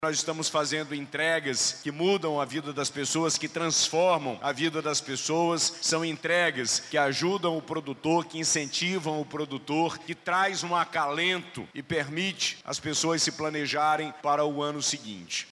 Durante o evento, o governador Wilson Lima destacou que o programa permite aos pequenos produtores se planejarem para as safras seguintes.